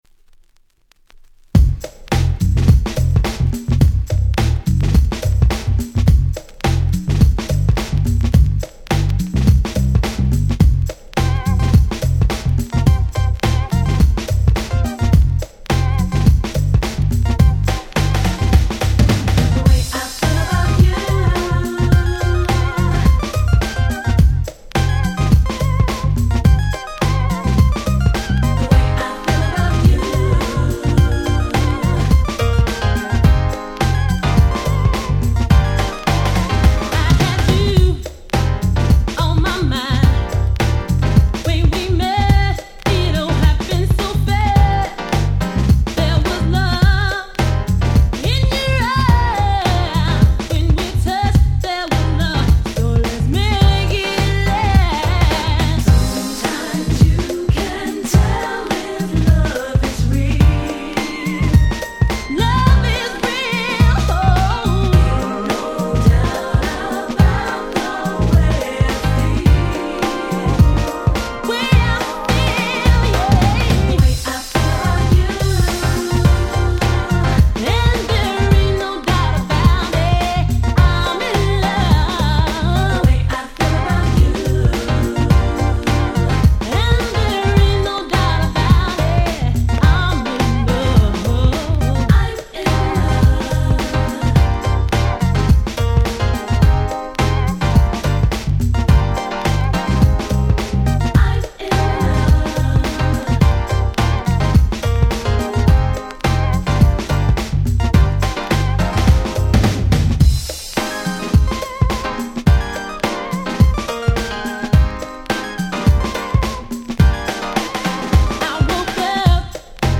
93' Nice Cover R&B !!